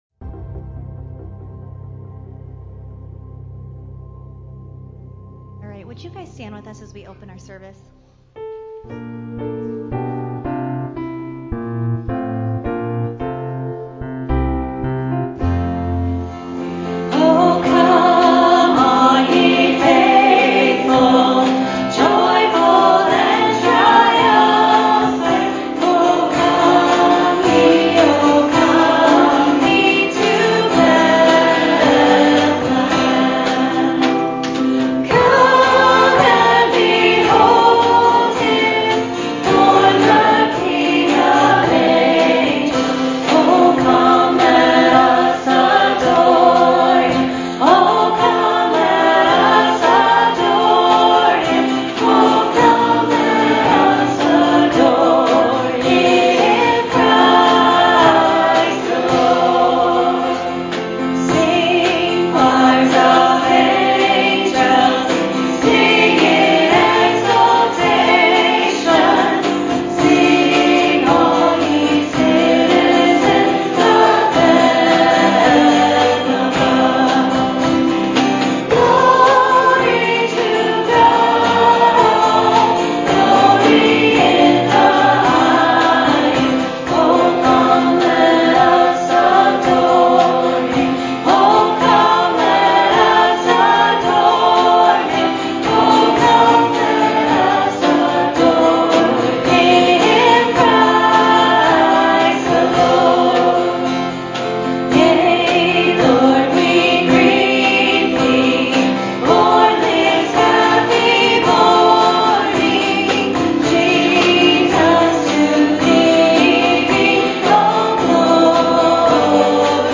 Christmas Eve Candlelight Service